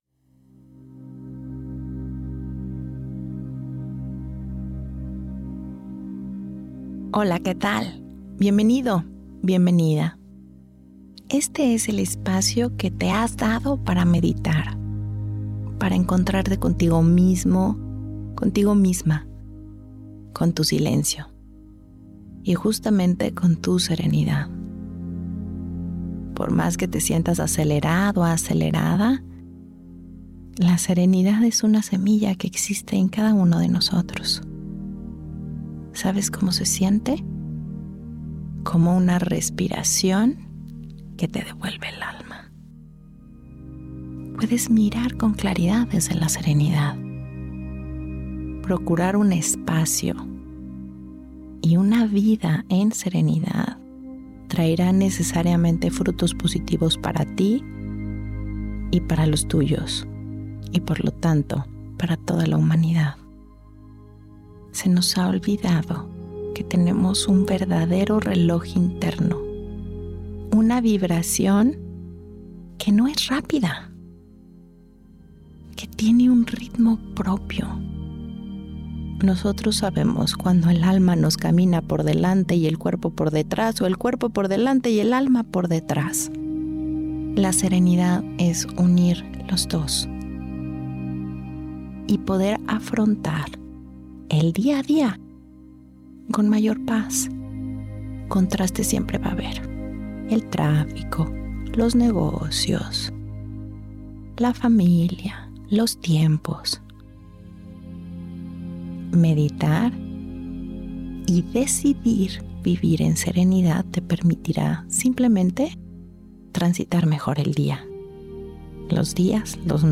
Meditaciones Eva Serena Esta Eva nos conecta con la decisión de mantener en armonía nuestro ser interior a pesar del caos externo.